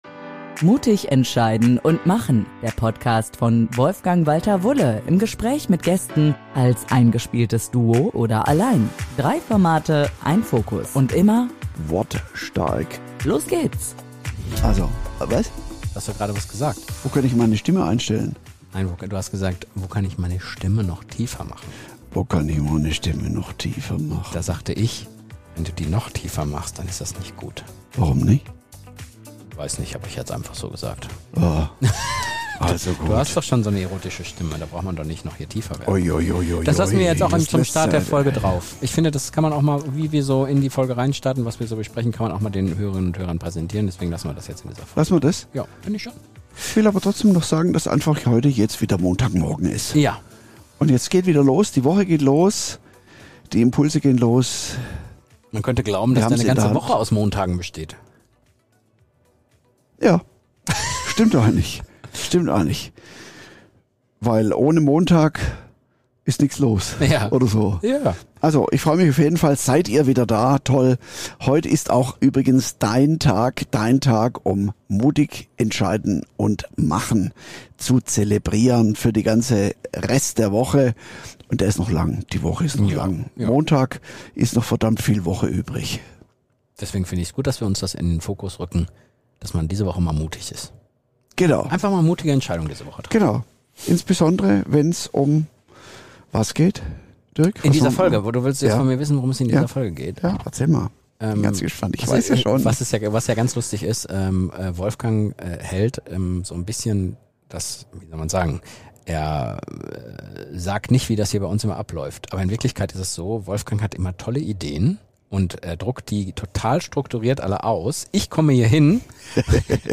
Sie zeigen, warum Wachstum und Erfolg ohne Reibung nicht entstehen können – und weshalb Harmonie oft Stillstand bedeutet. Mit Humor, Ehrlichkeit und spürbarer Freundschaft diskutieren die beiden, wie konstruktive Auseinandersetzung, klare Regeln und echte Offenheit in Teams und Beziehungen zu Entwicklung führen.